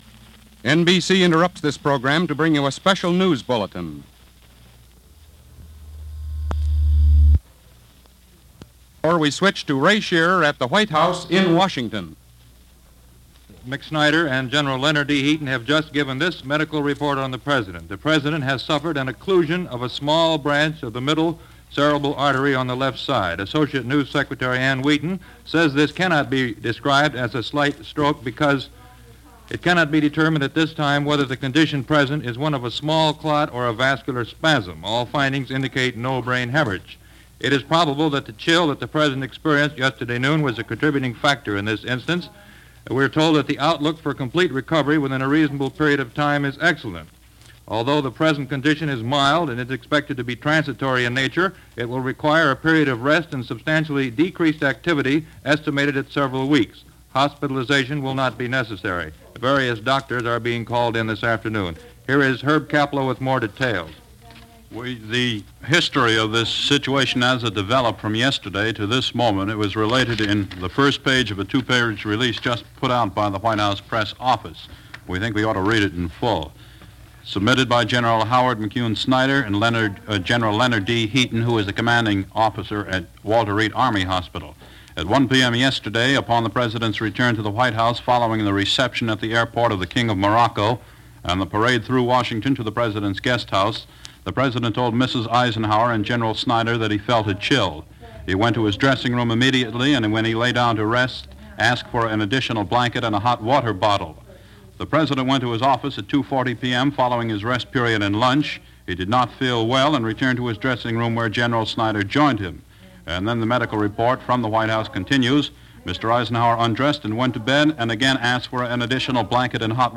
So as a reminder that almost seventy years ago we were facing a crisis of uncertainty, here is the first bulletin as it was broadcast on November 26, 1957 over the NBC Radio Network, cutting into one of the few remaining dramatic shows carried by the network at the time.